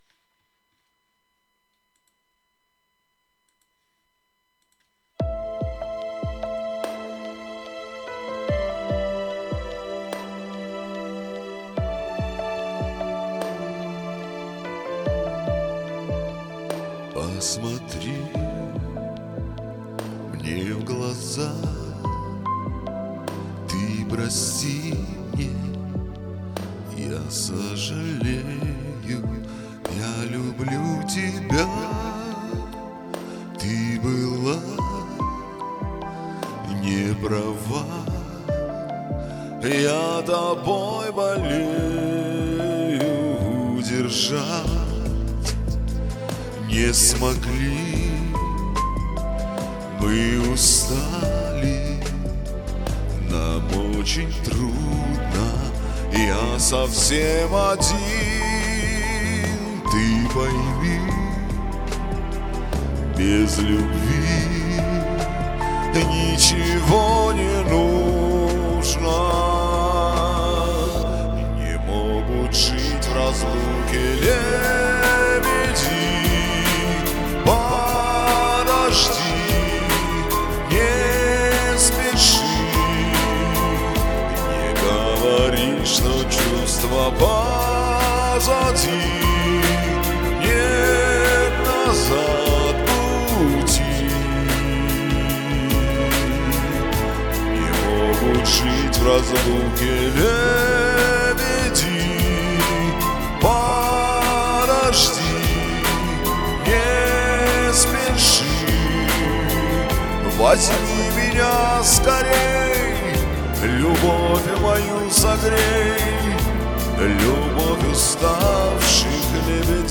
Не точное интонирование, выпадение из ритма